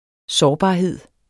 Udtale [ ˈsɒːˌbɑˌheðˀ ]